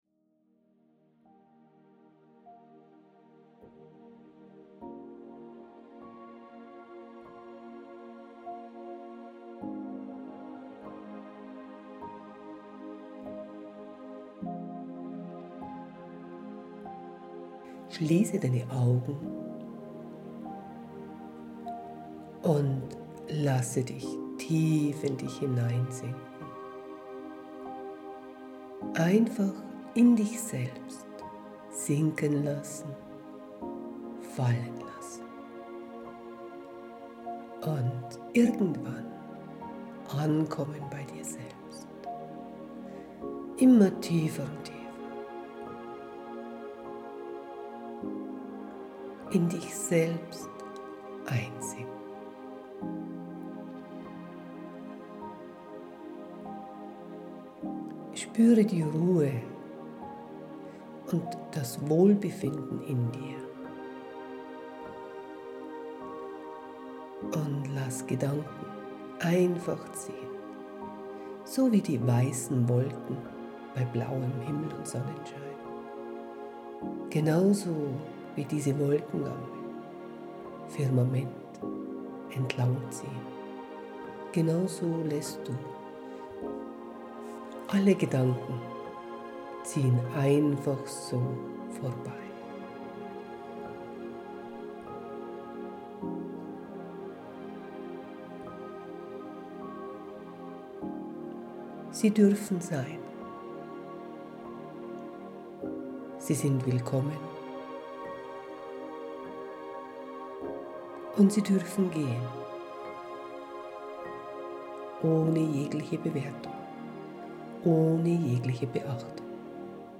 Heutiges Geschenk: eine kleine Seelenmeditation als Download